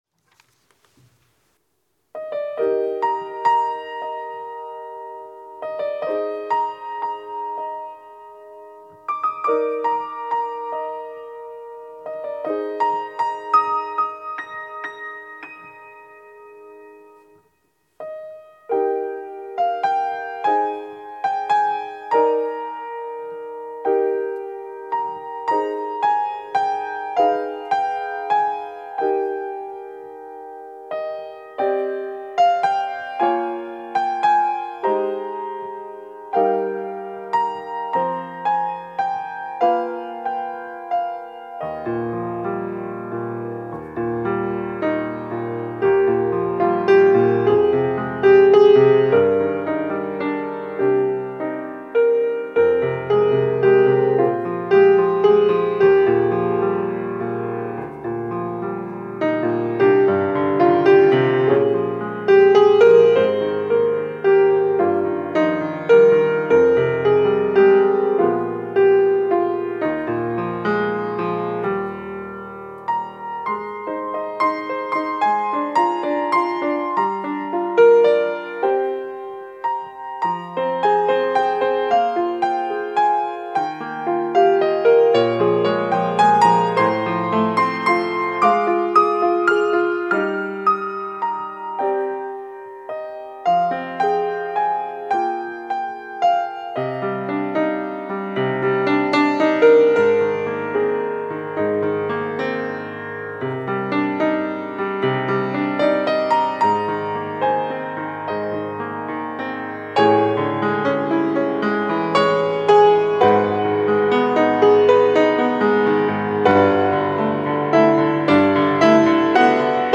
특송과 특주 - 너 예수께 조용히 나가